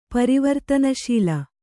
♪ pari vartana śila